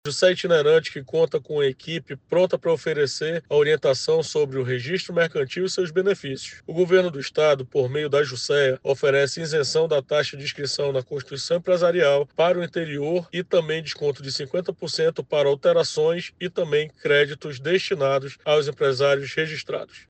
Em continuidade ao calendário de atendimento ao interior do Amazonas, a Jucea Itinerante tem o objetivo de esclarecer as dúvidas e ainda orientar o público empresarial sobre a isenção de taxas para constituições empresariais, os descontos direcionados ao empresariado local, assim como esclarecer sobre os procedimentos de alterações ou reativações de empresas, além dos benefícios de créditos destinados aos empresários registrados, como explica o Presidente da Jucea, em exercício – Edmundo Ferreira Brito Netto.